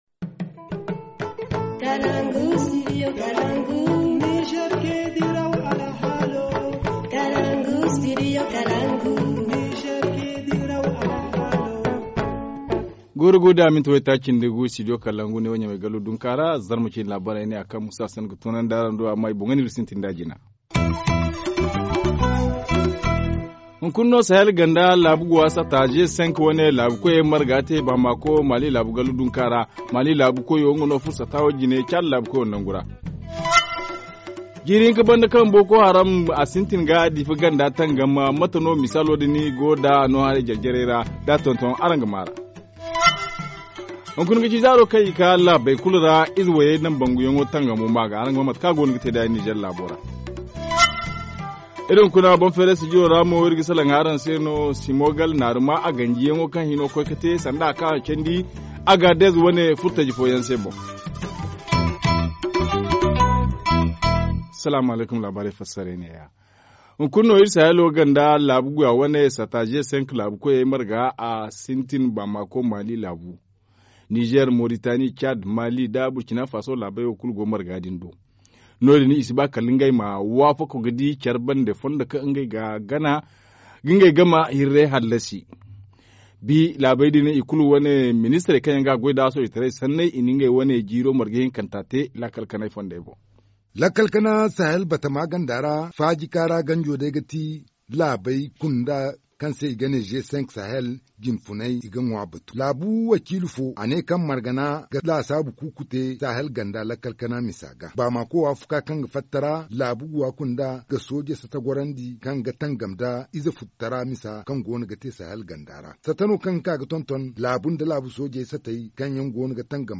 Journal du 6 février 2017 - Studio Kalangou - Au rythme du Niger